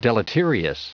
Prononciation du mot deleterious en anglais (fichier audio)
Prononciation du mot : deleterious